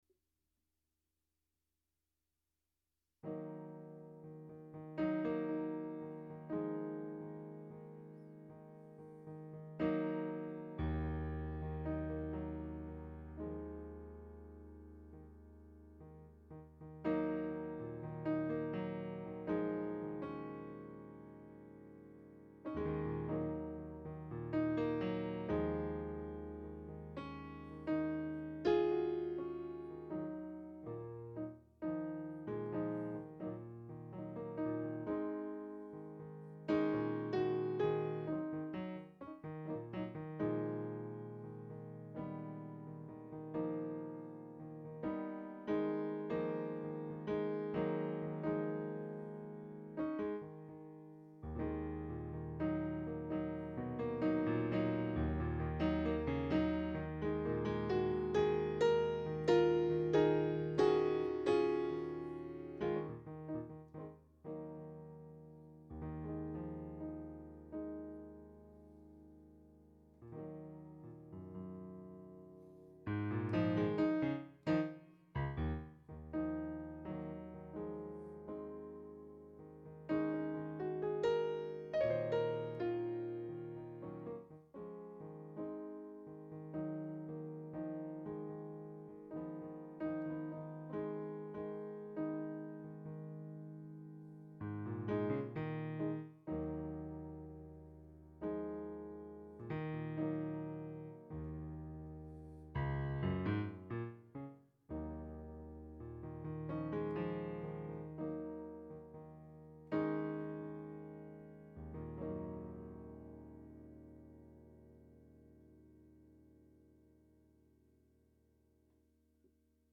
Improvisations réalisées entre juillet et novembre 2014.